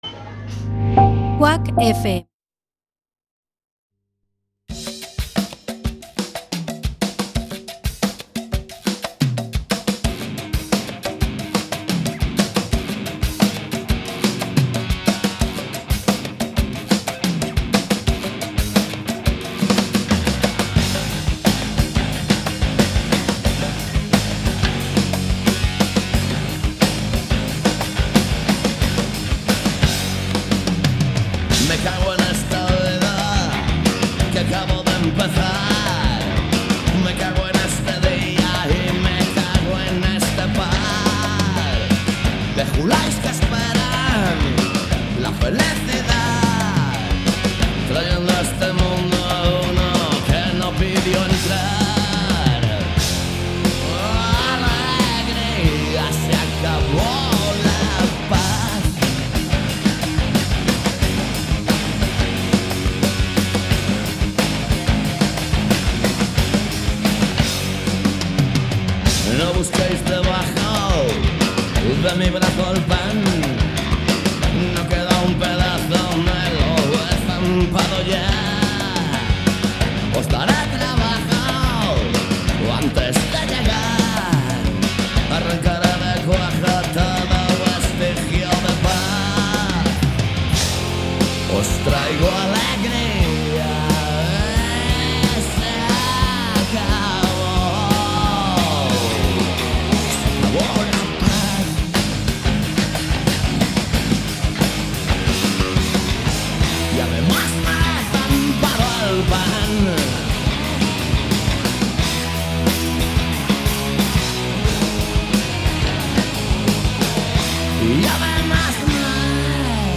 Programa emitido cada mércores de 19:00 a 20:00 horas.